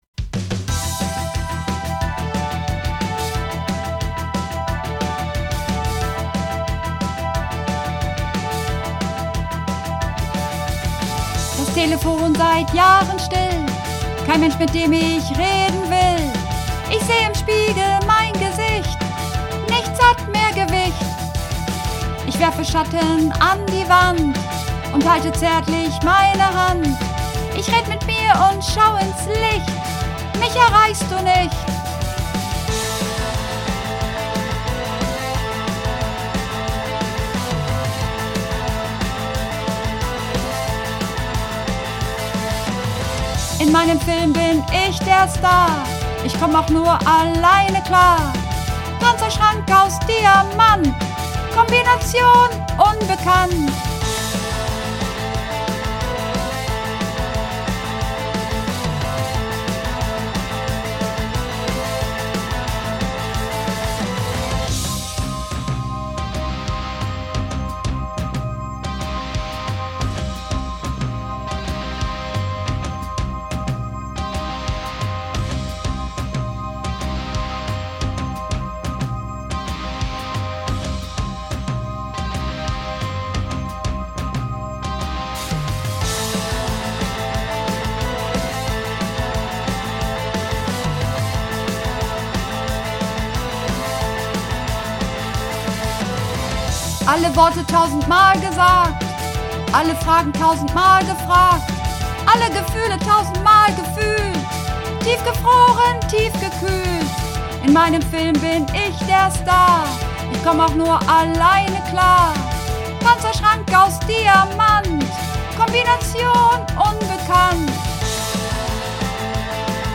Übungsaufnahmen - Eiszeit
Eiszeit (Sopran)
Eiszeit__4_Sopran.mp3